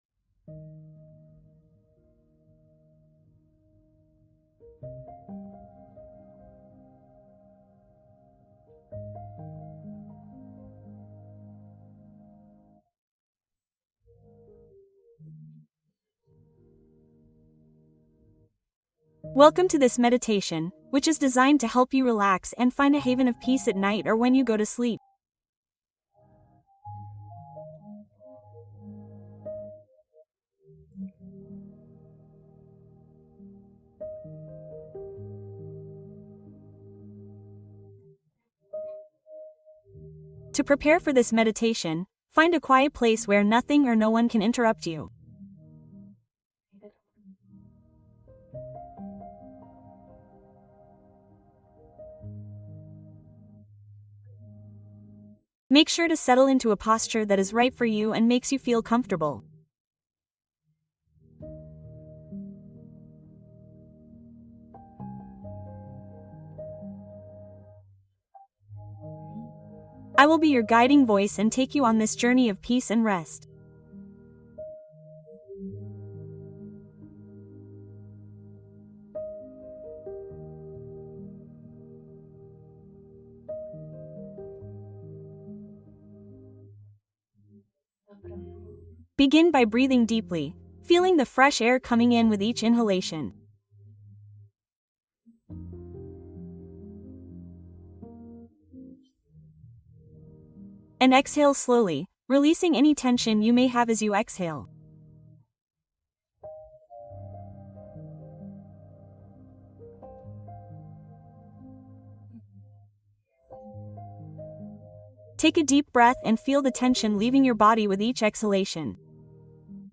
Meditación matutina 15 minutos ❤ Inicia el día con presencia